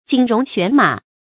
仅容旋马 jǐn róng xuán mǎ
仅容旋马发音